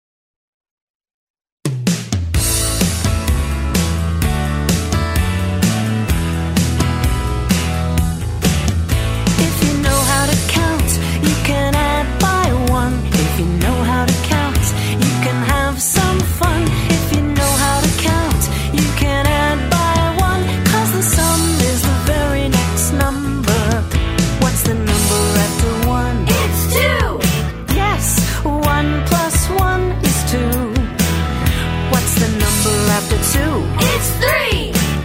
Children's Song Lyrics and Sound Clip